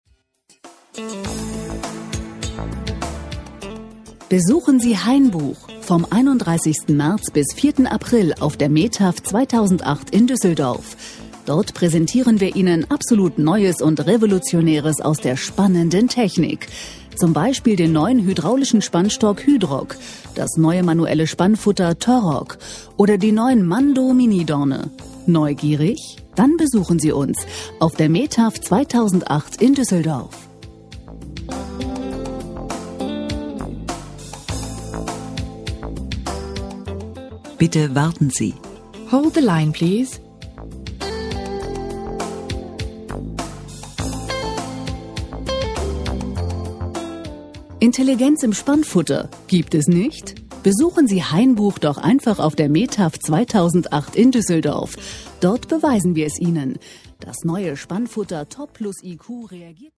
Kundenportrait_Werbespot_Hainbuch_2.mp3